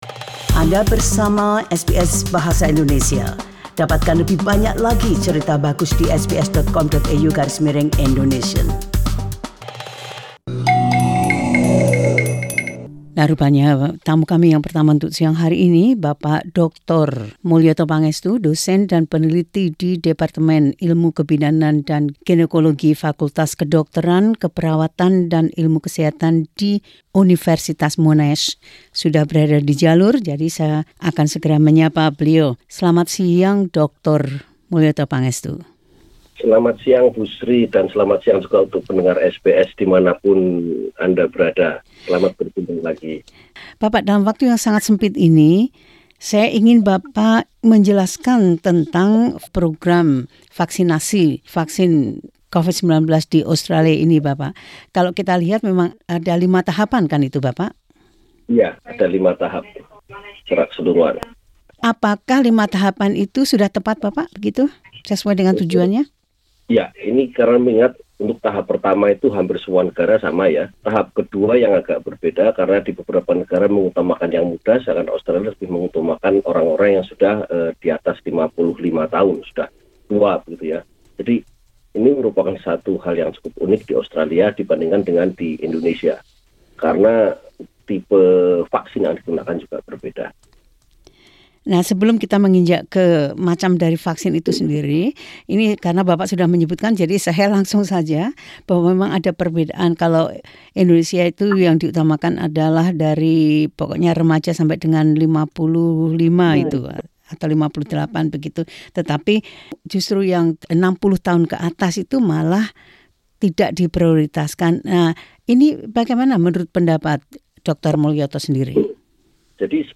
In the time between the initial broadcast of this interview (01 Feb) and today's upload (08 Feb) the Indonesian government has adjusted its policy on vaccinating older people.